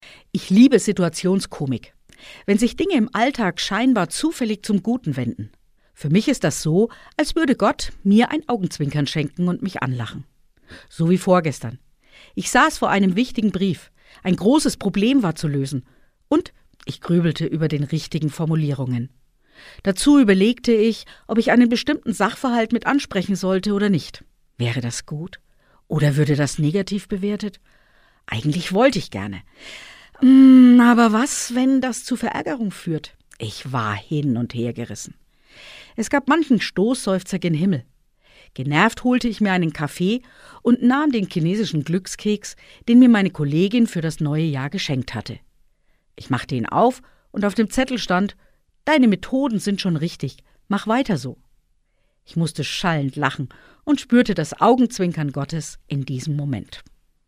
Autorin und Sprecherin ist